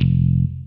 TR PICK GUIT 1.wav